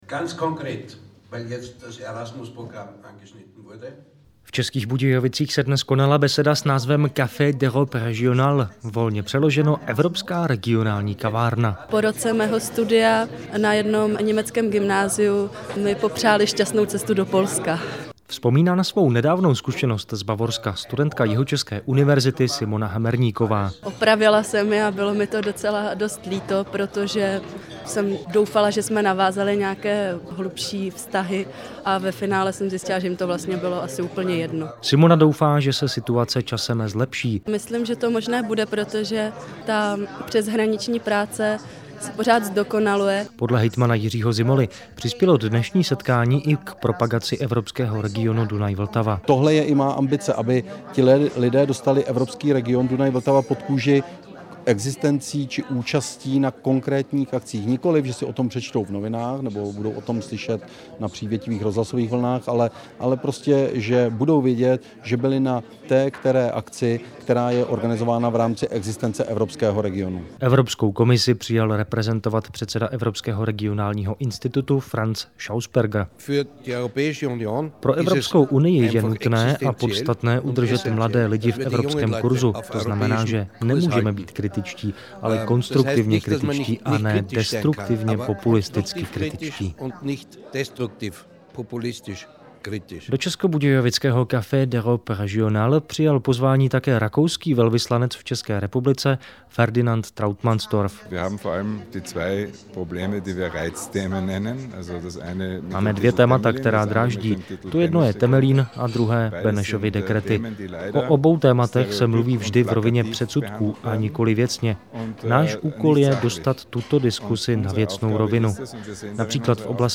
reportaz-cesko-nemecke-vztahy.mp3